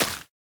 wet_grass1.ogg